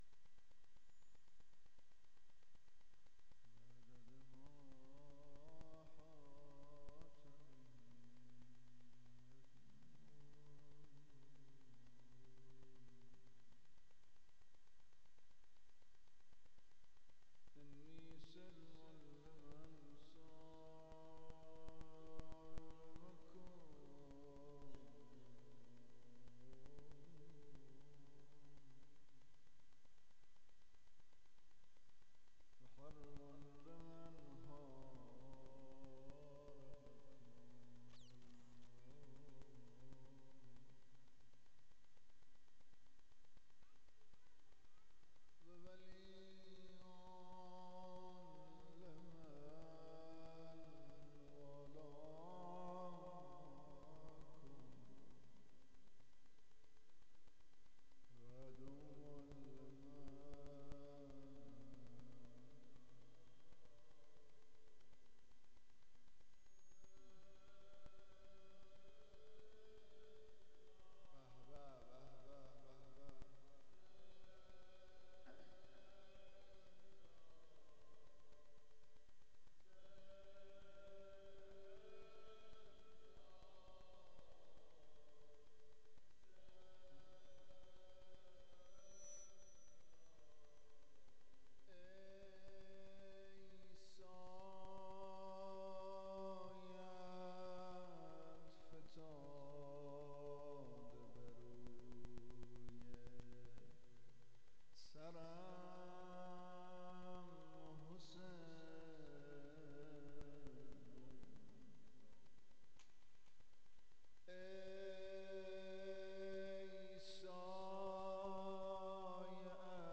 باب الحوائج - مدّاحی شب اوّل